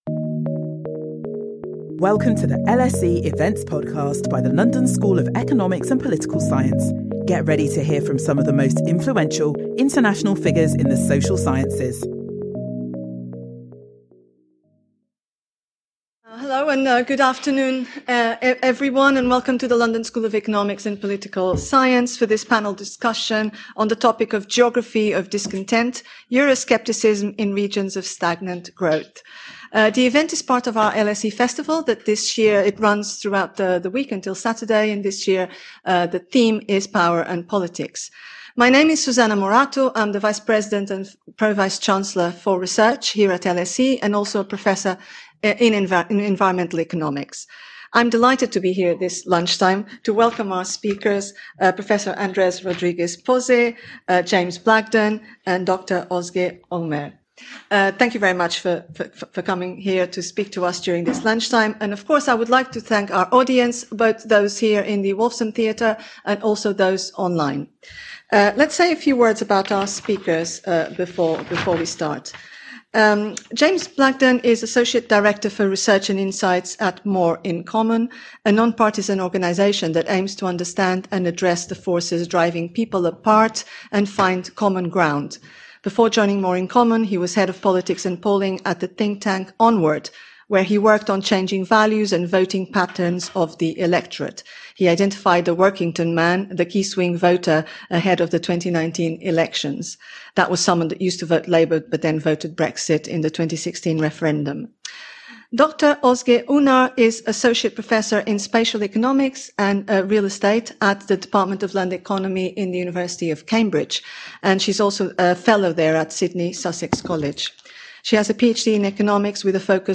Our panel examine issues in economic geography, development traps within the EU, and political discontent, and consider what these findings mean for the global challenges of populism, inequality, and political instability.